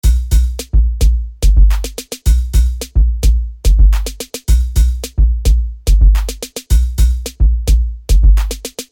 描述：这很热，毫无疑问，这与任何流派都相配。
Tag: 108 bpm Hip Hop Loops Drum Loops 1.50 MB wav Key : Unknown